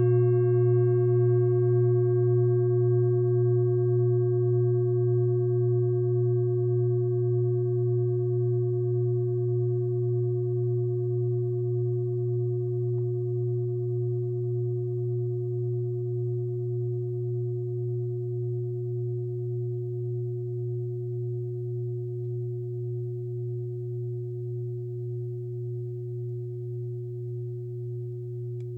Klangschale TIBET Nr.31
Sie ist neu und ist gezielt nach altem 7-Metalle-Rezept in Handarbeit gezogen und gehämmert worden.
(Ermittelt mit dem Filzklöppel)
Die 37. Oktave dieser Frequenz liegt bei 241,56 Hz. In unserer Tonleiter liegt dieser Ton nahe beim "H".